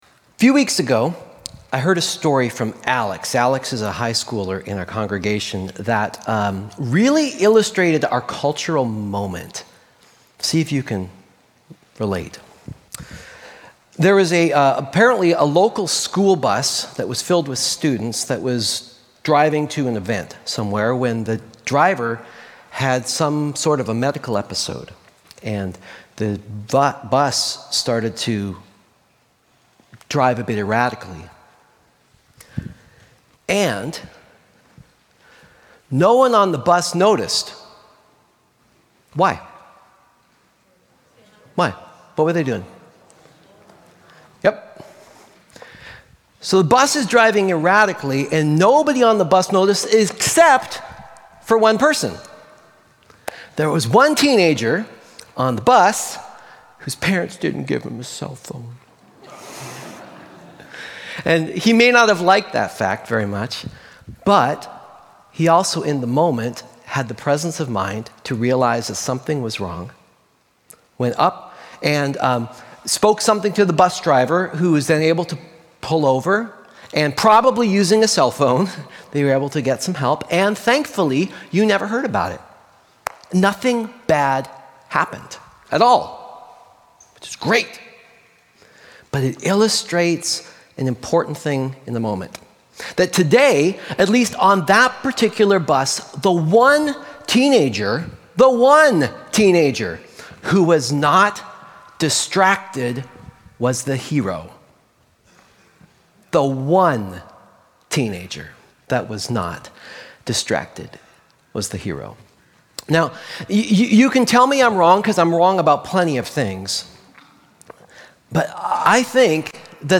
Sermons from Columbia Grove Covenant Church in East Wenatchee WA.